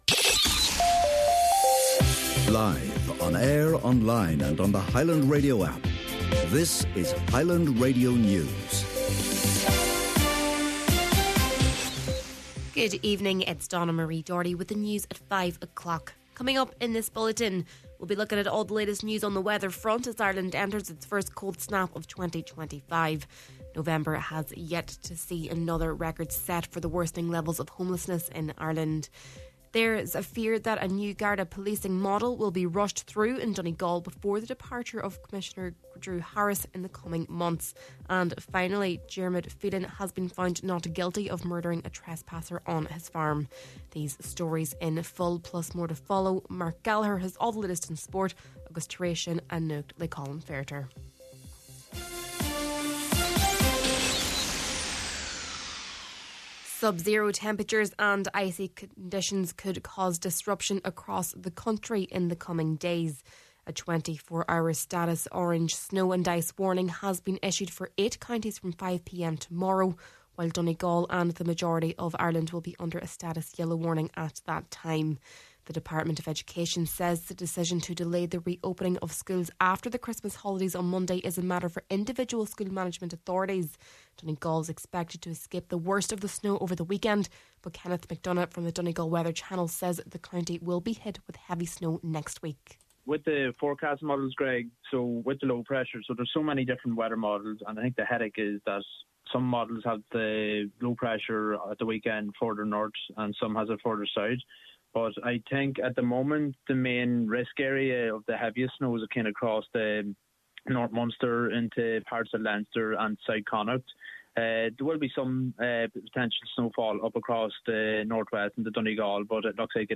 Main Evening News, Sport, an Nuacht and Obituaries – Friday, January 3rd